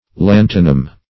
Lantanum \Lan"ta*num\